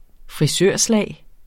Udtale [ fʁiˈsøɐ̯ˀˌslæˀj ]